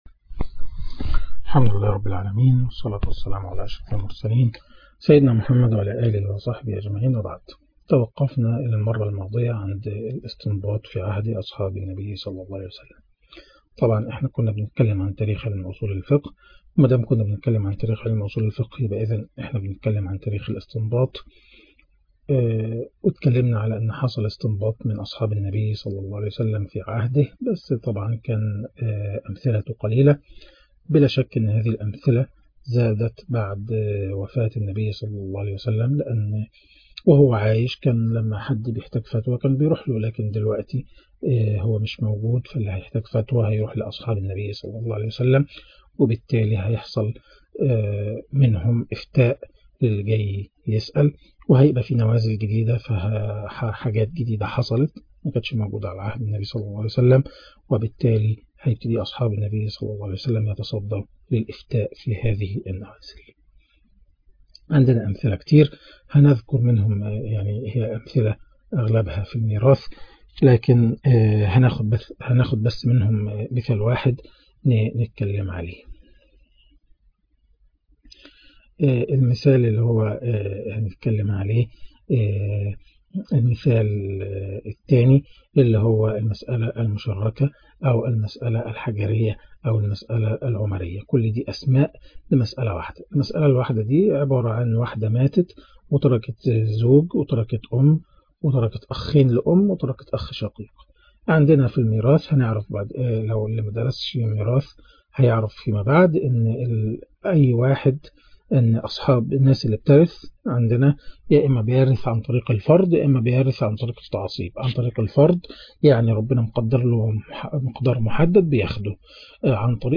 أصول الفقه -2- معهد ابن تيمية الفرقة الأولي